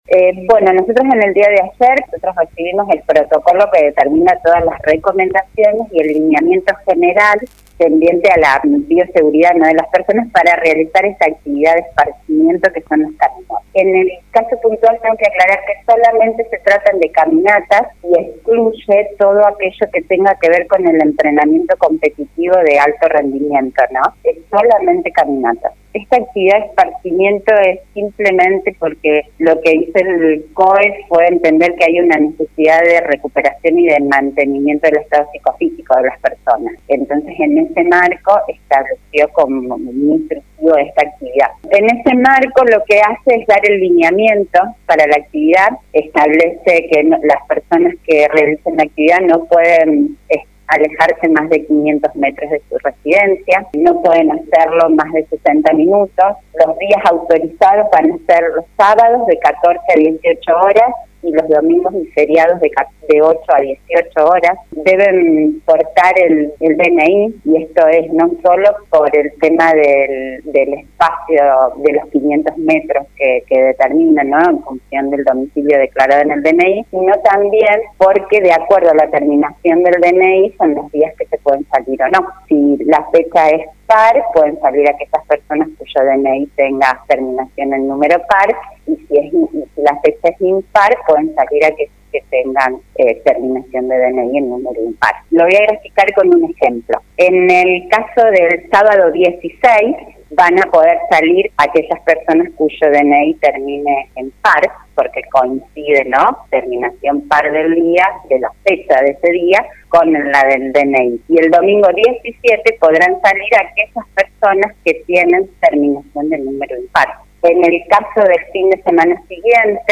Sobre el particular, la secretaria de gobierno del municipio, Ingrid Grasso, en conversaciones con La Urbana explicó que en Leones se aplicará tal como fue elaborado y dijo que el control lo llevarán adelante personal policial y de tránsito.